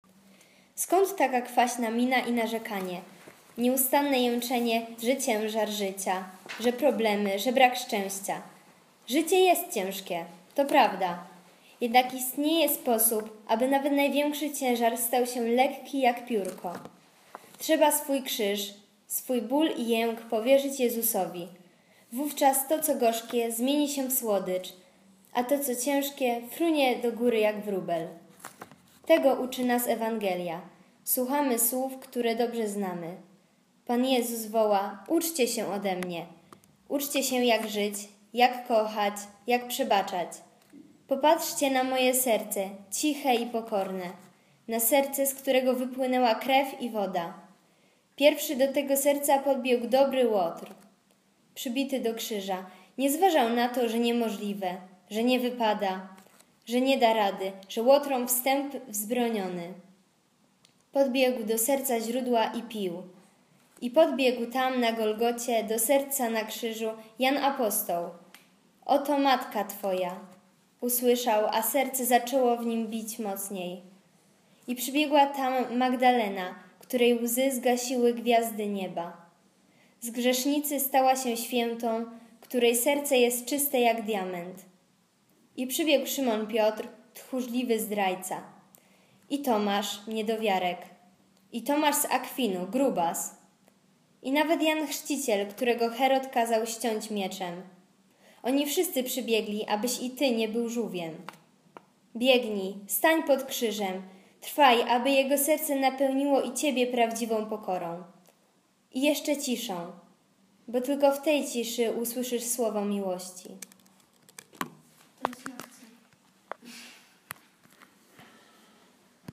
Komentarz do Ewangelii z dnia 14 lipca 2016 czyta